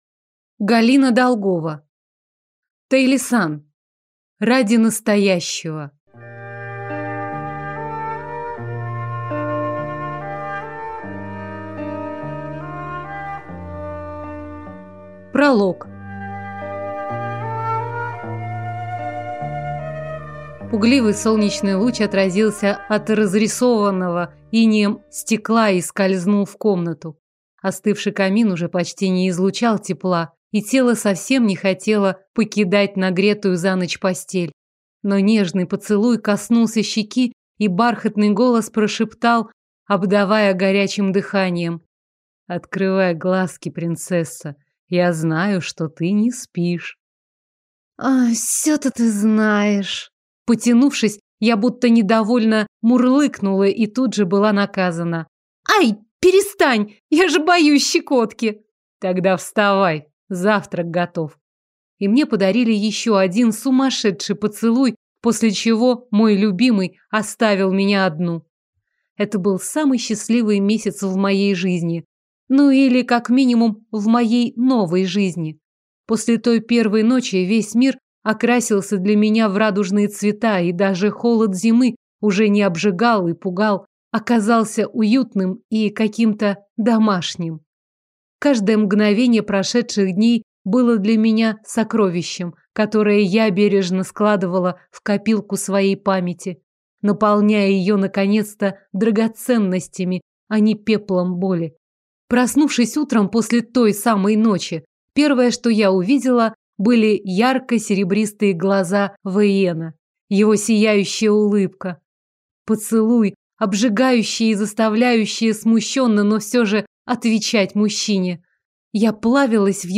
Аудиокнига Тайлисан. Ради настоящего - купить, скачать и слушать онлайн | КнигоПоиск